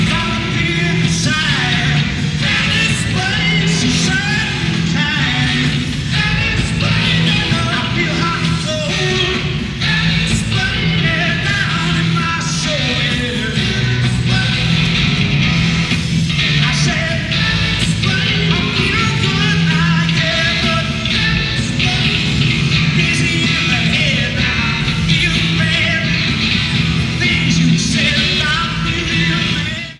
Format/Rating/Source: CD - C- - Audience
Comments: Fair audience recording
Sound Samples (Compression Added):